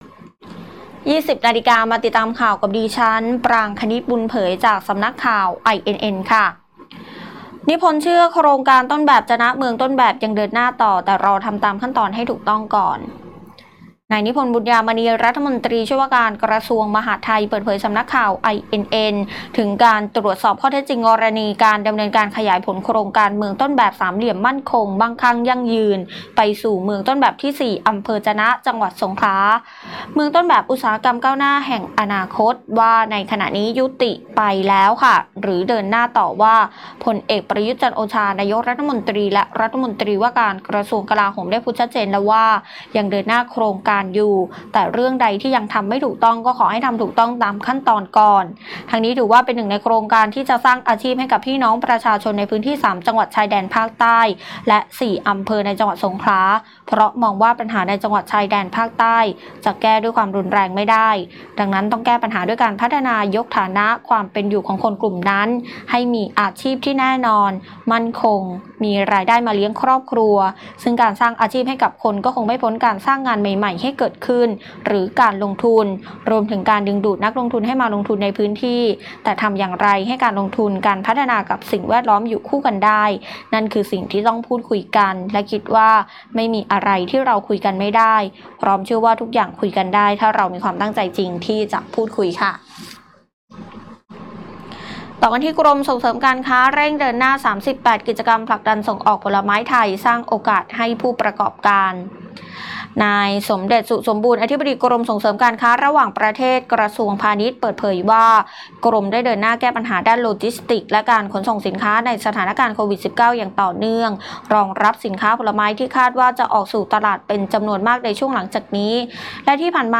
ข่าวต้นชั่วโมง 20.00 น.